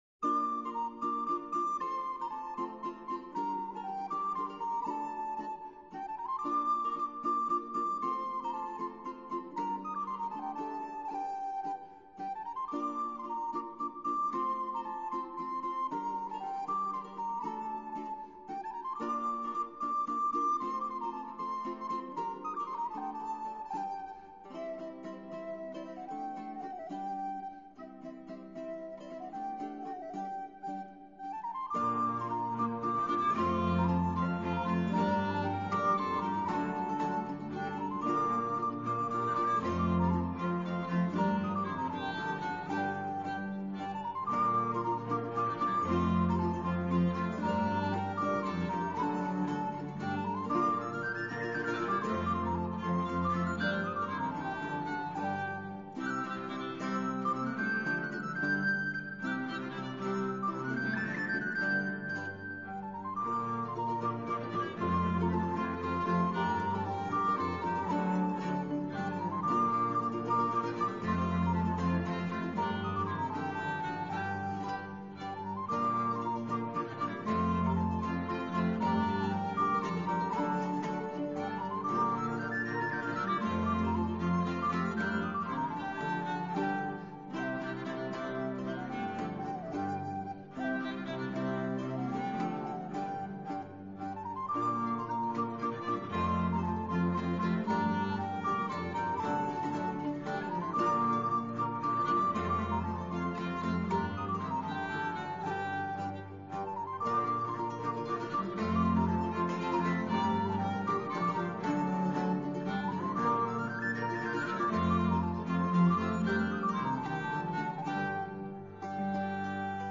Ensemble : 4 voix et 5 ou 6 instruments Gaillarde Romanesque